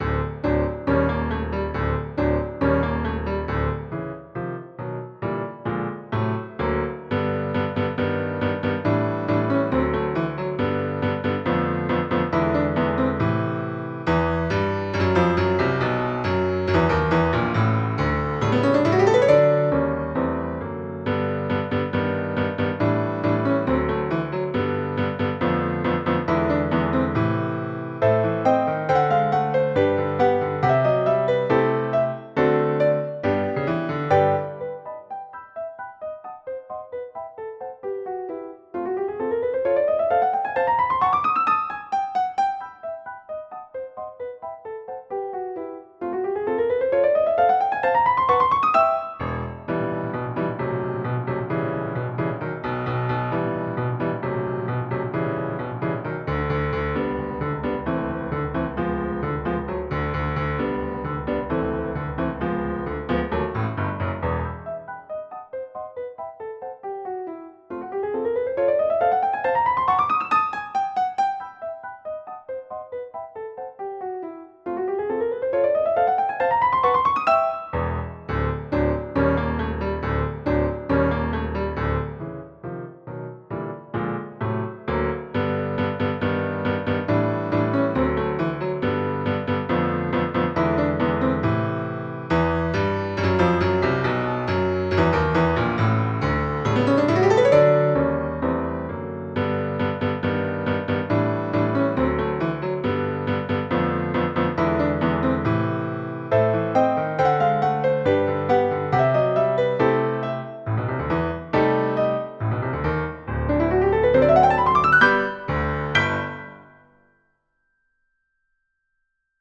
SYNTHESISED RECORDINGS LIBRARY
Piano